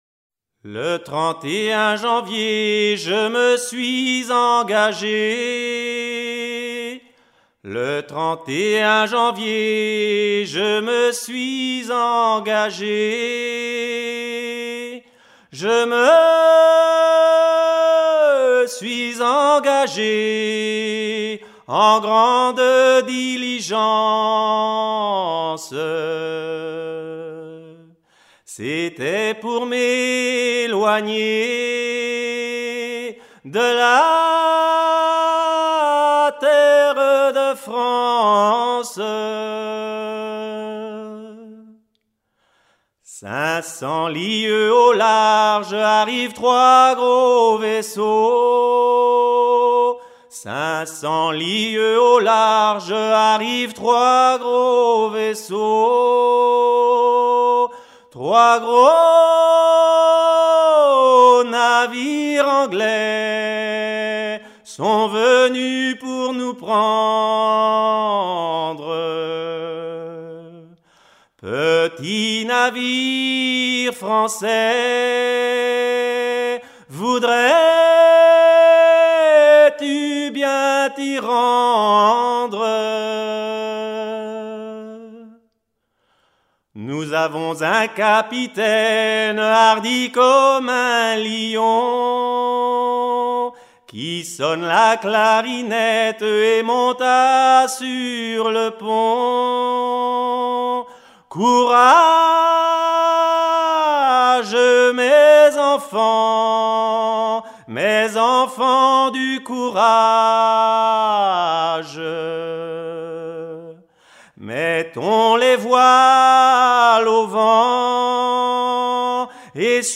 chanson recueillie en 2000
Pièce musicale éditée